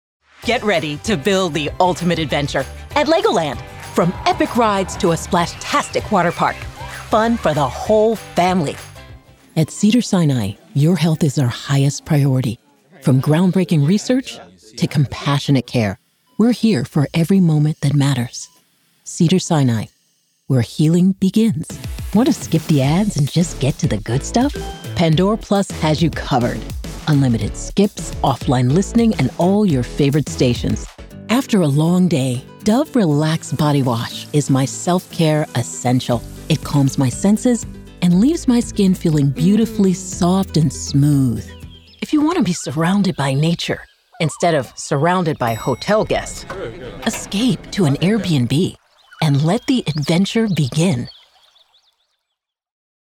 Warm Conversational Authoritative Genuine Knowledgeable
Commercial Demo
Southern